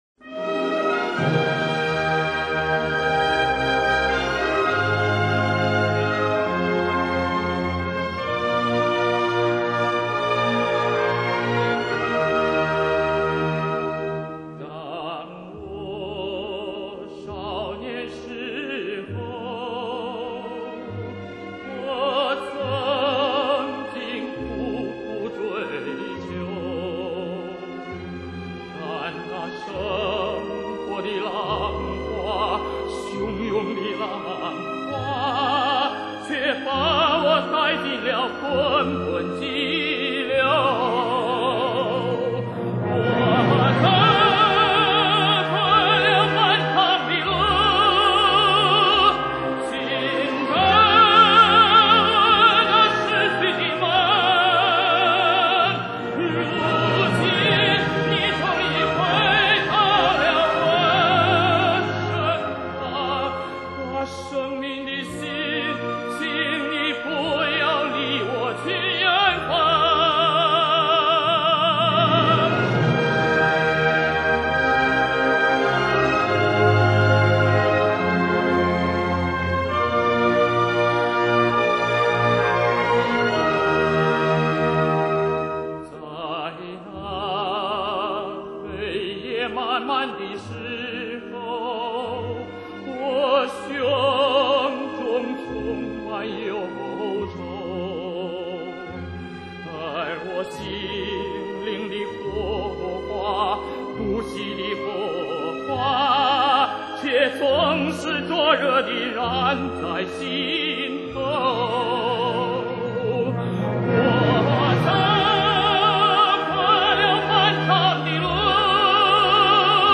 这首歌大气而抒情
充分发挥了其意大利美声演唱的特点